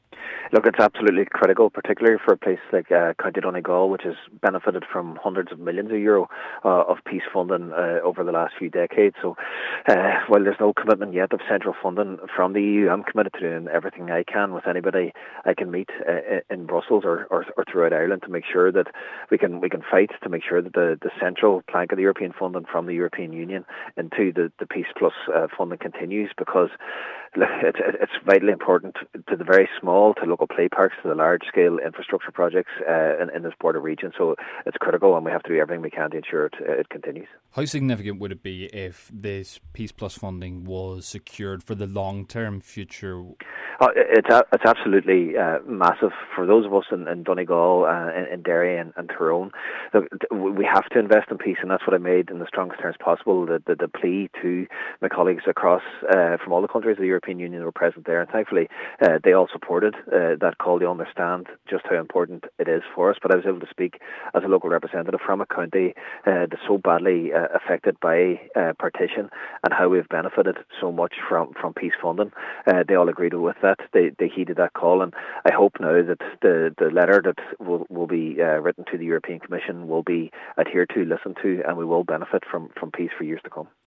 Cllr Jack Murray was speaking in favour of protecting the money for Donegal and the border communities